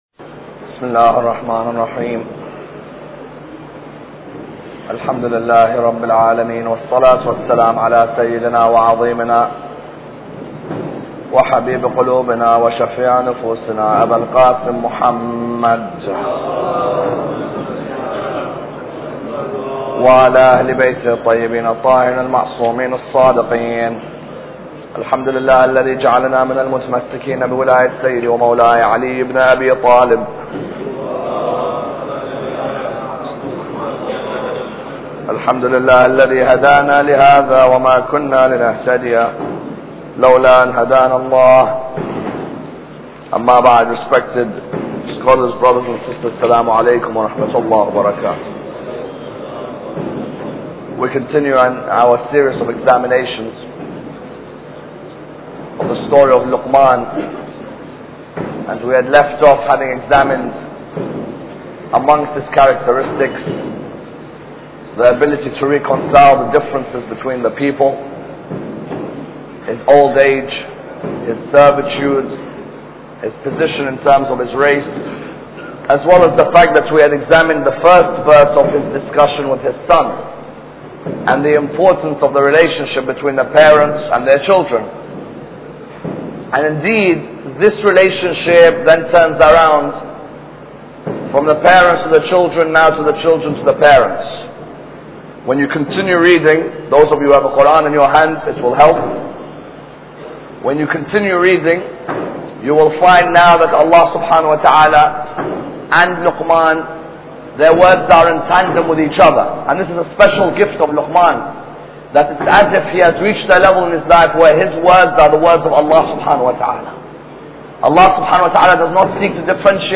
Lecture 8